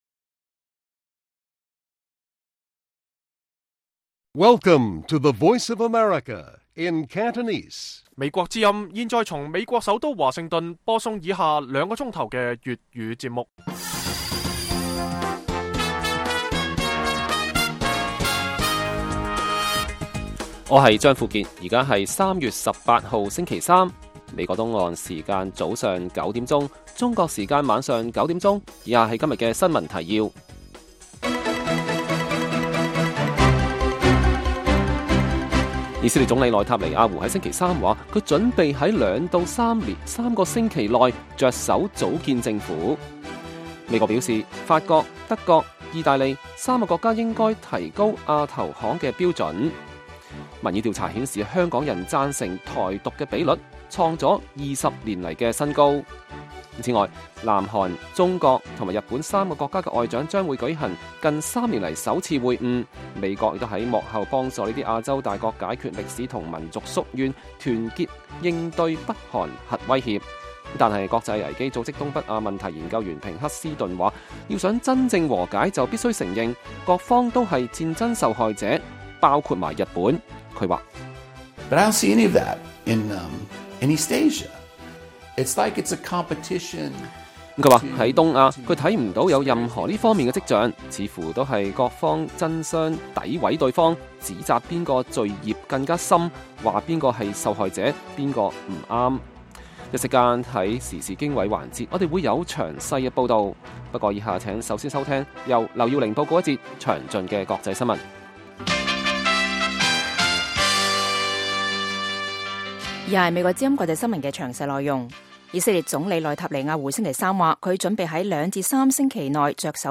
粵語新聞 晚上9-10點
北京時間每晚9－10點 (1300-1400 UTC)粵語廣播節目。